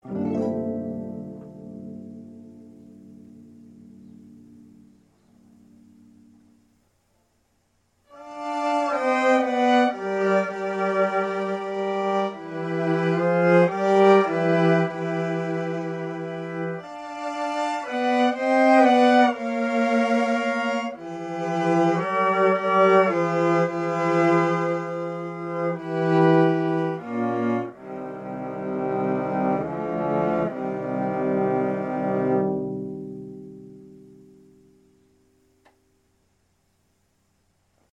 4way PD pitch shifter. Running on a Linux computer that cost less than some stompboxes